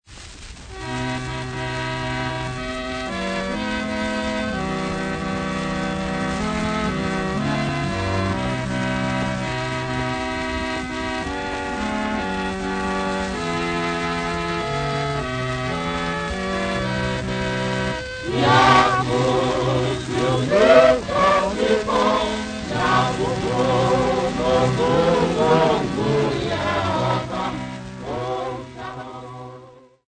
Xhosa Sacred Singers
Popular music--Africa
Field recordings
sound recording-musical
Xhosa religious song accompanied by a piano